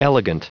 Prononciation du mot elegant en anglais (fichier audio)
Prononciation du mot : elegant